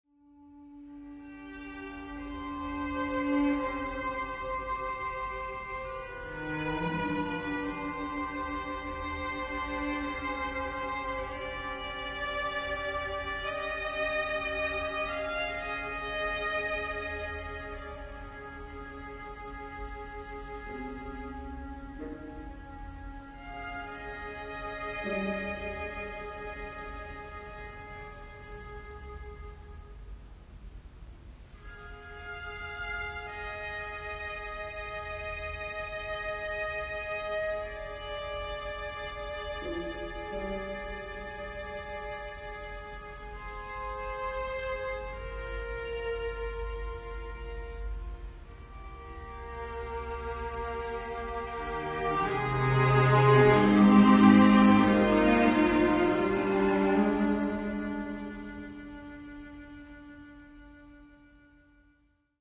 streichquintett